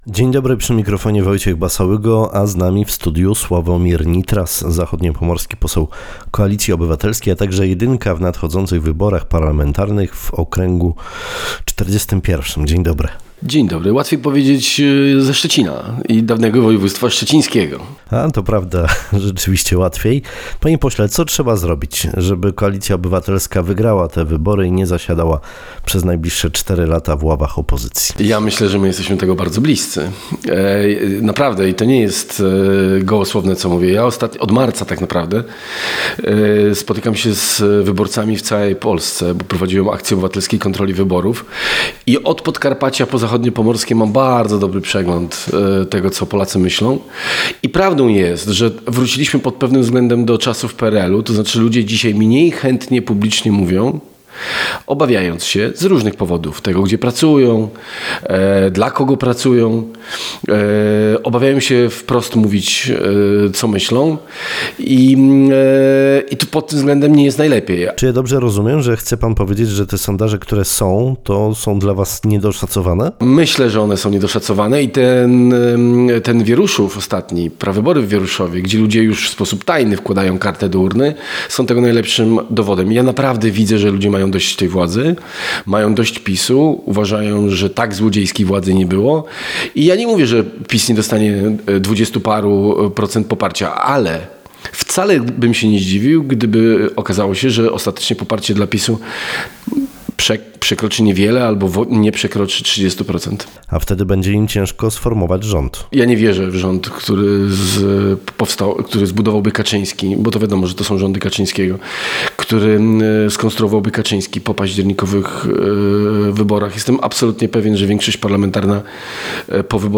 Zachodniopomorski poseł Koalicji Obywatelskiej Sławomir Nitras był Gościem Rozmowy Dnia w Twoim Radiu. Polityk był pytany o pomysł debaty ze Zbigniewem Boguckim, sposób na wygrane wybory, a także o słynne czeki rozdawane przez polityków PiS-u.